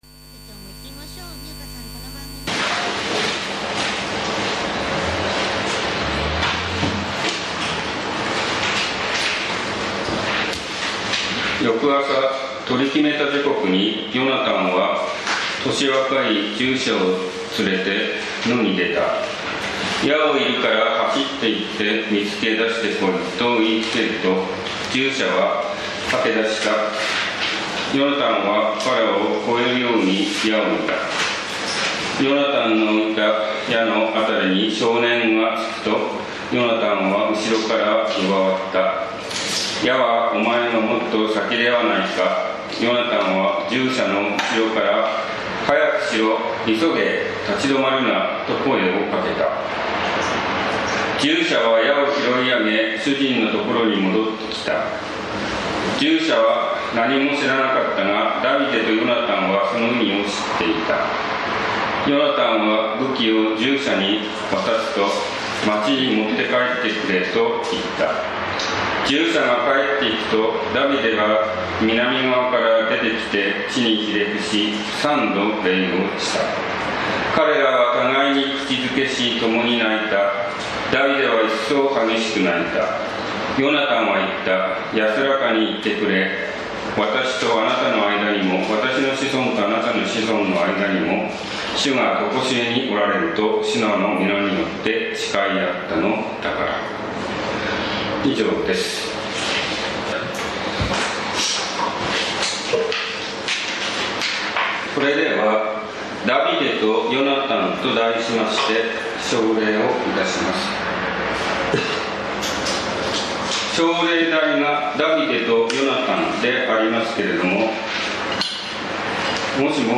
ダビデとヨナタン 宇都宮教会 礼拝説教
栃木県鹿沼市のプロテスタント教会。